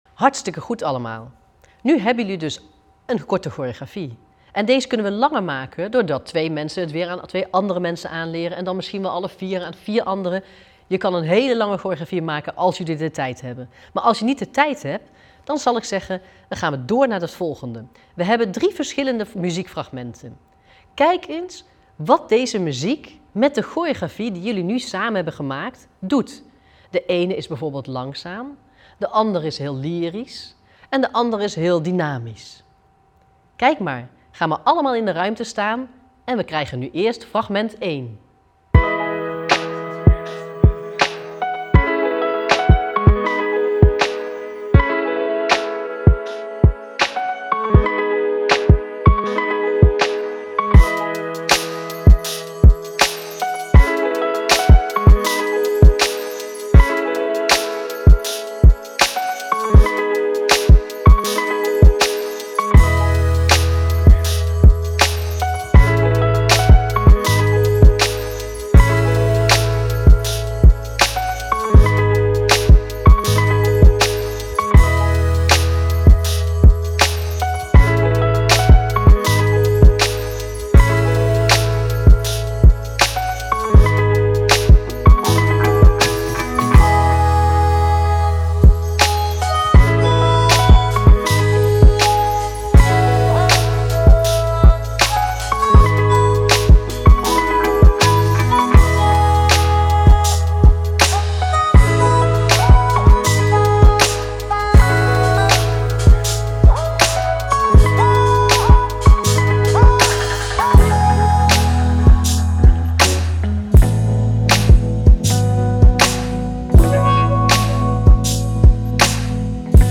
Audio 2.7 t/m 2.9 Verschillende stijlen muziek om op te dansen.
2.7 Alfabet coole muziek - 4:32 minuten -